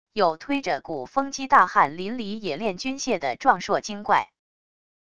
有推着鼓风机大汗淋漓冶炼军械的壮硕精怪wav音频